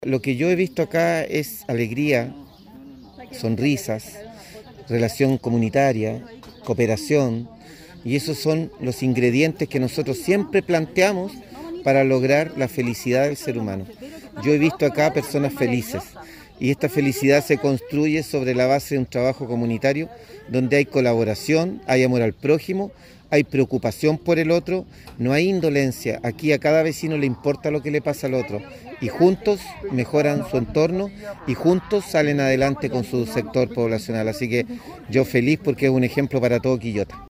Alcalde-Luis-Mella-1-1.mp3